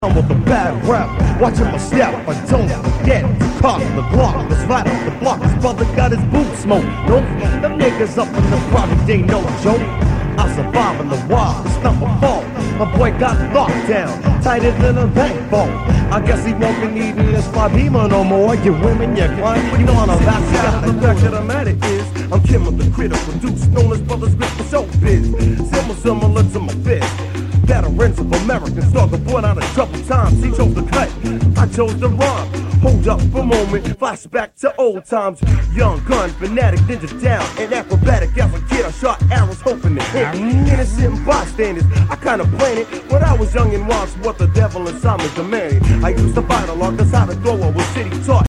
rap/hip-hop